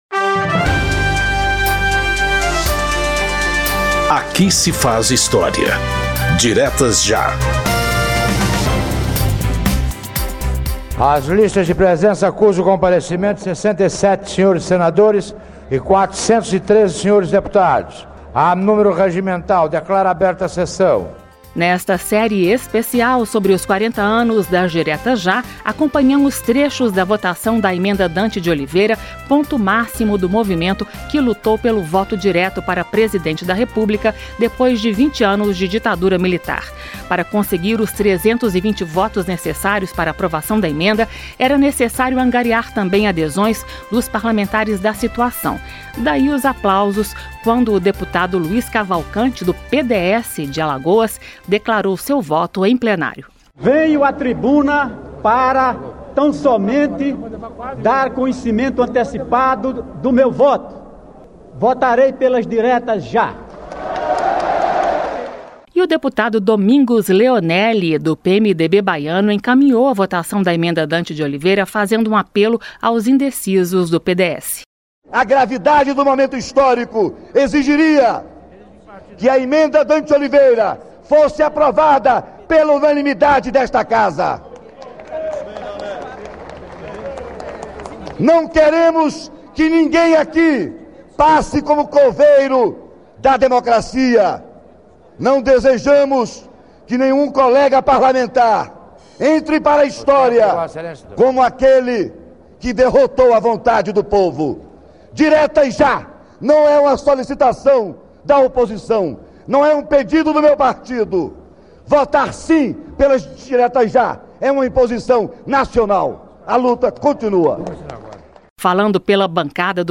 Nesta série especial sobre os 40 anos das Diretas Já, acompanhamos trechos da votação da Emenda Dante de Oliveira, ponto máximo do movimento que lutou pelo voto direto para Presidente da República, depois de 20 anos de ditadura militar.
Daí, os aplausos quando o deputado Luiz Cavalcante, do PDS de Alagoas, declarou seu voto, em plenário.
Um programa da Rádio Câmara que recupera pronunciamentos históricos feitos no Parlamento por deputados ou agentes públicos, contextualizando o momento político que motivou o discurso.